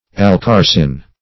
Search Result for " alkarsin" : The Collaborative International Dictionary of English v.0.48: Alkarsin \Al*kar"sin\, n. [Alkali + arsenic + -in.]